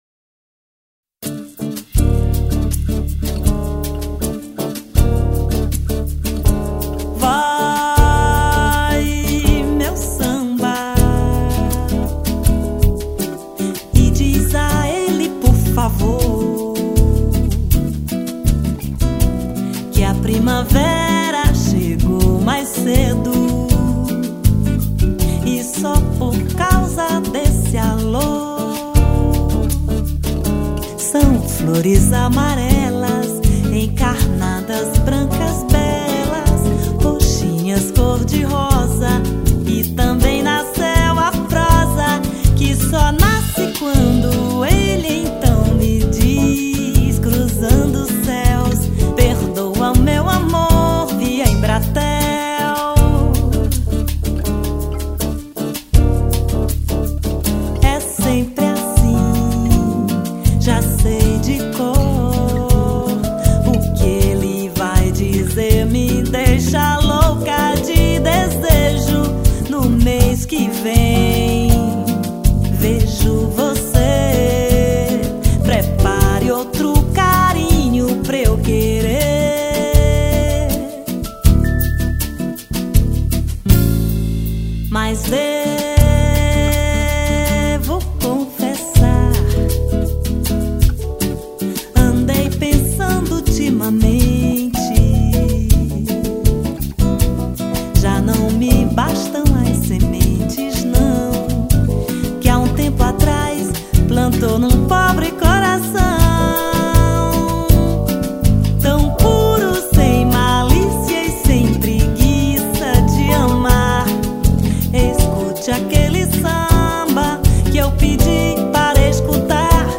1570   03:10:00   Faixa:     Bossa nova
Baixo Elétrico 6
Bateria
Violao Acústico 6
Piano Elétrico